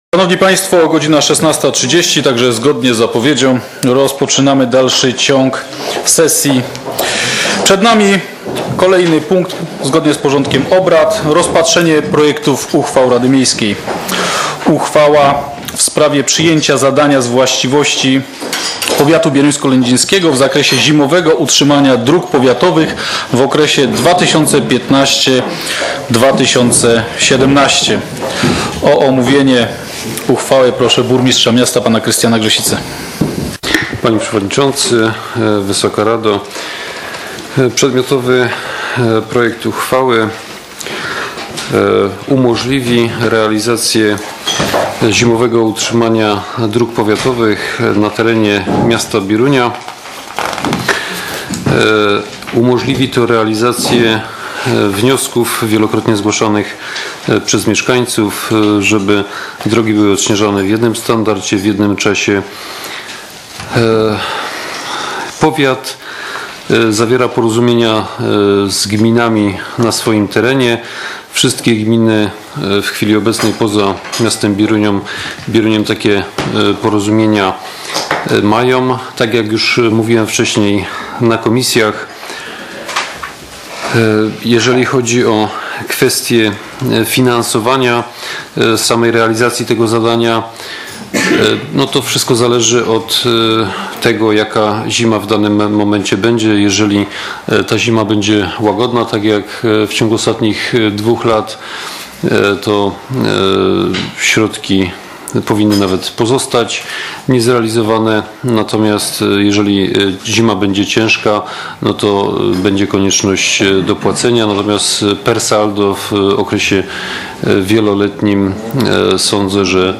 z obrad V sesji Rady Miejskiej w Bieruniu, która odbyła się w dniu 28.05.2015 r. w dużej sali szkoleń Urzędu Miejskiego w Bieruniu